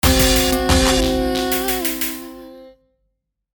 Play, download and share wave original sound button!!!!